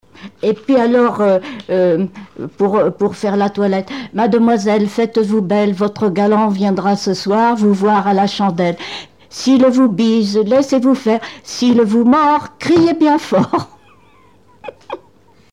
Rondes à baisers et à mariages fictifs
formulette enfantine : amusette
Pièce musicale inédite